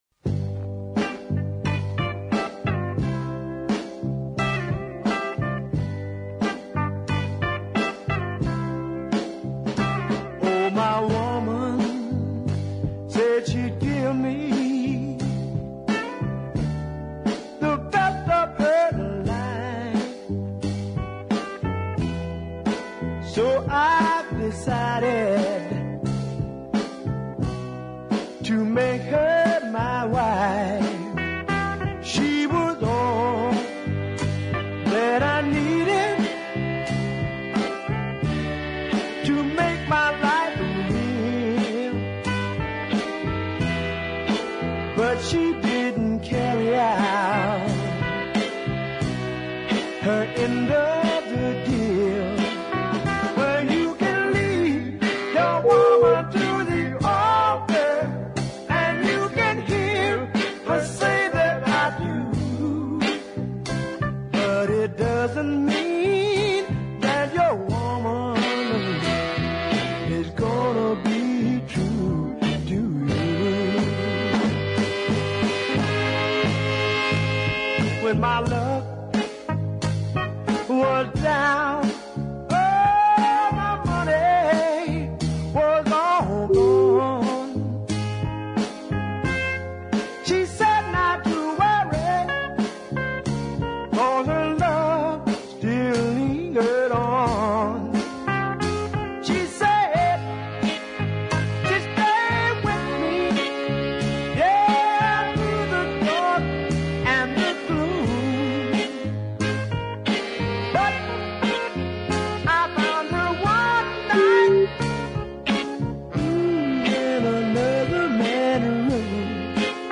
In 1967 he was in Memphis recording tracks
guitarist